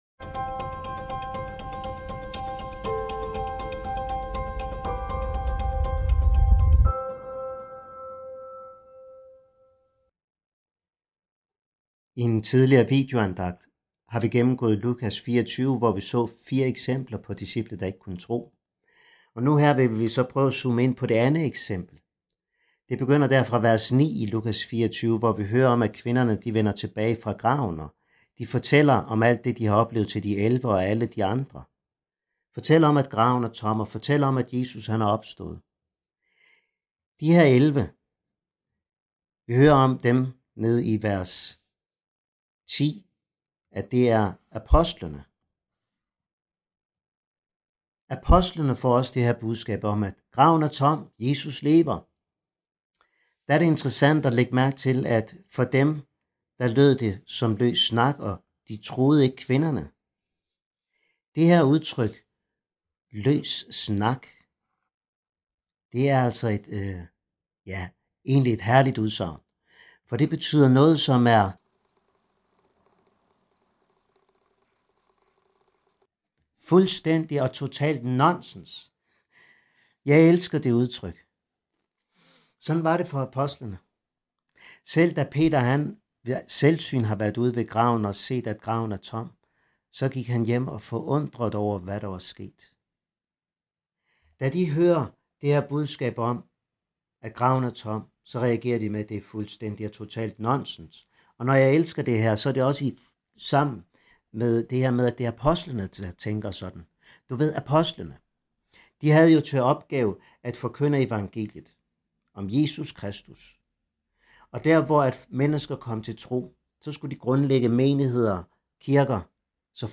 Afspil undervisning